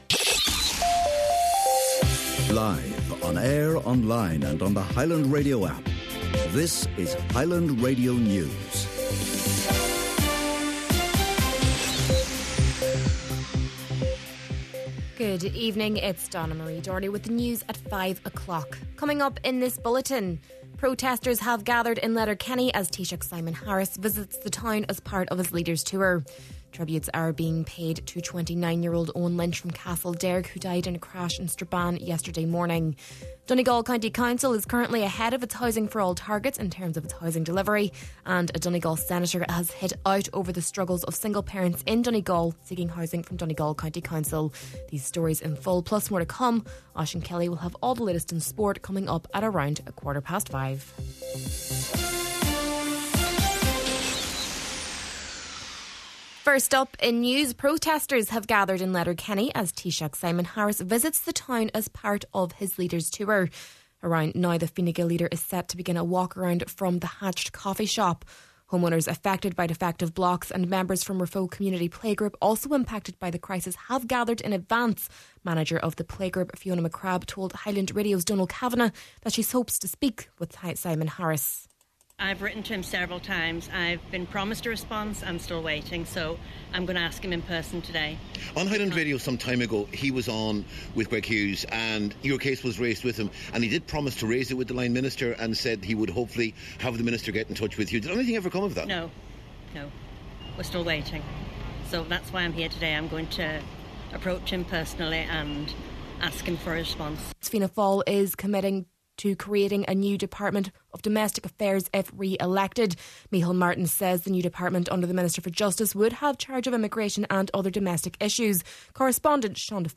Main Evening News, Sport and Obituary Notices – Monday, November 11th